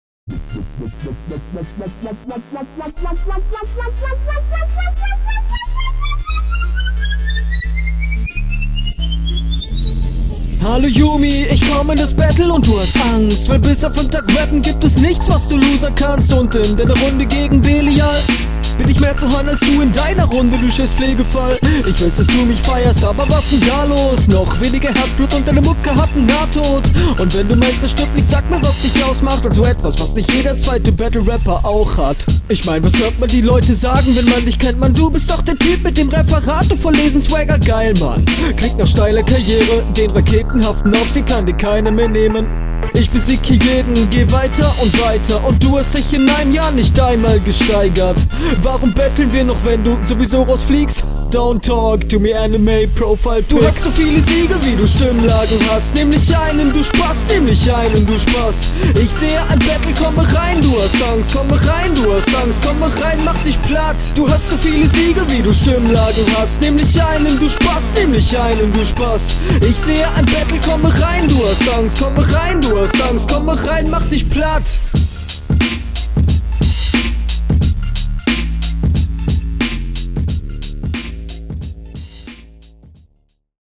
Auf dem eigenen Beatpick kommst du flowlich deutlich besser als in der Rückrunde.